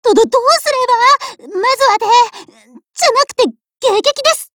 Cv-20211_warcry.mp3